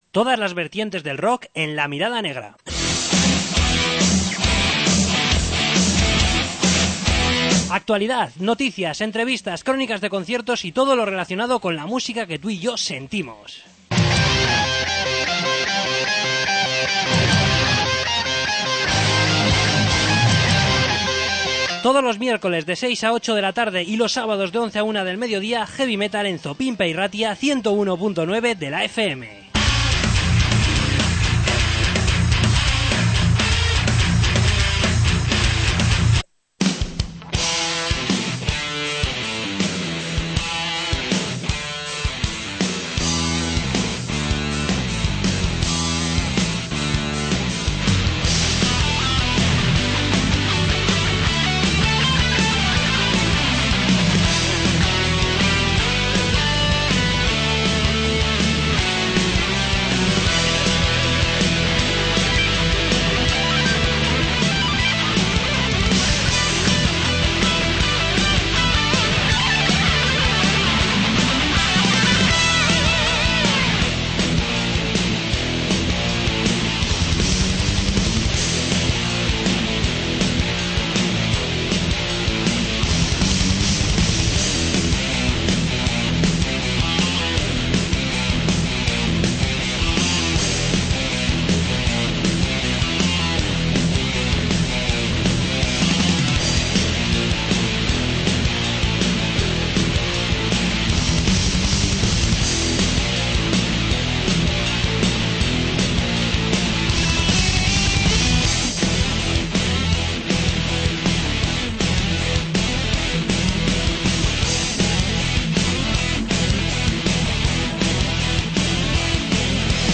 Entrevista con Airless